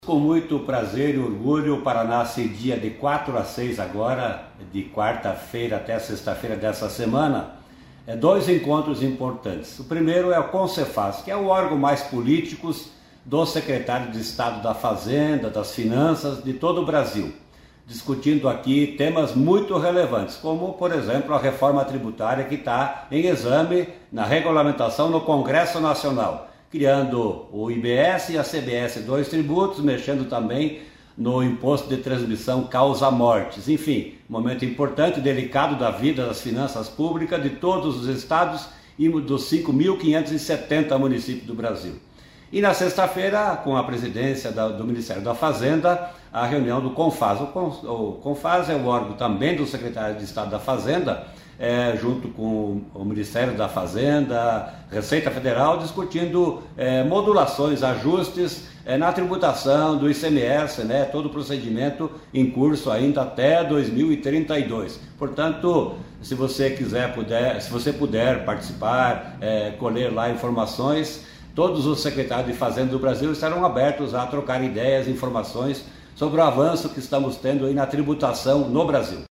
Sonora do secretário Estadual da Fazenda, Norberto Ortigara, sobre as reuniões do Comsefaz e Confaz nesta semana, em Foz